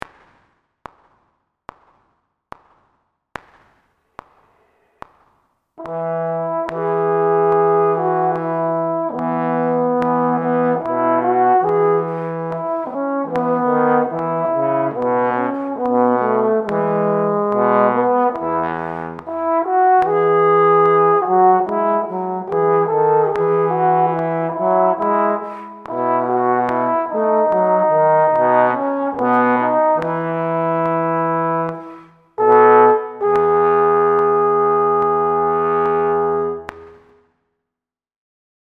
Here’s the audio of the (individual) harmony parts.
Maj-04-E.mp3